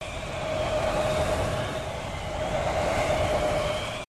tt_s_ara_cfg_whirlwind.ogg